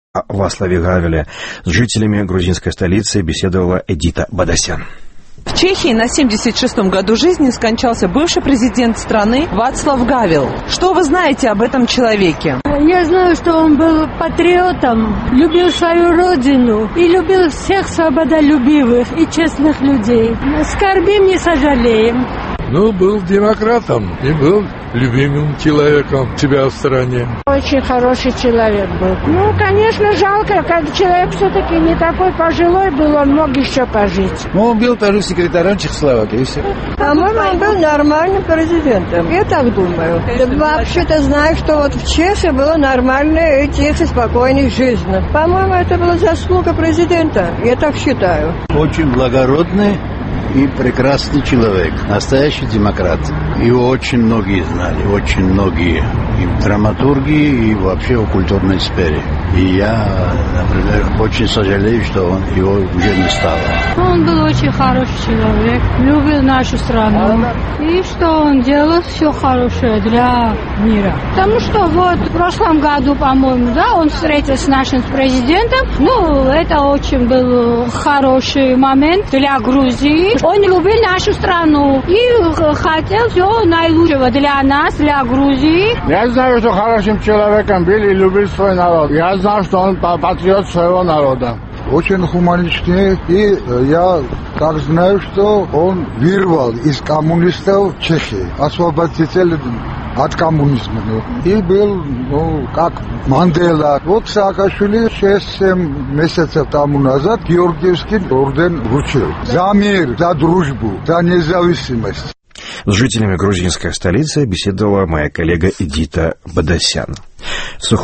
Голоса